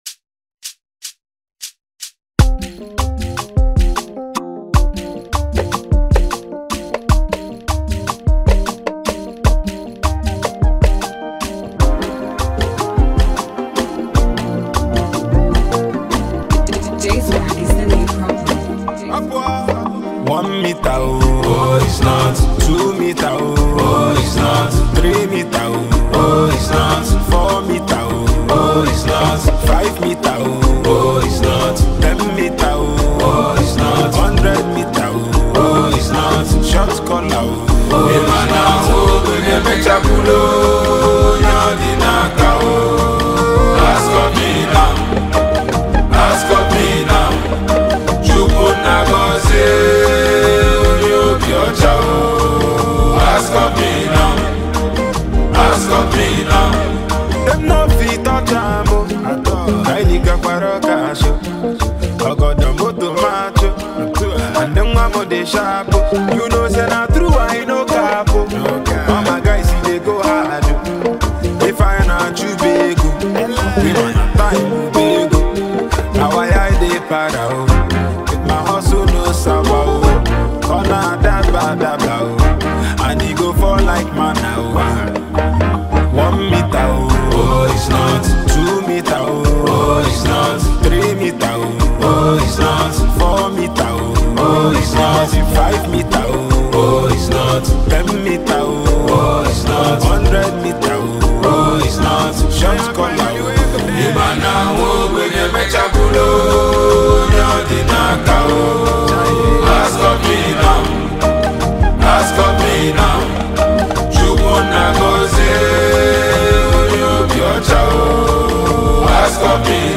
Well, he returns big with fresh, sweet Highlife melody.
Nigerian rapper and singer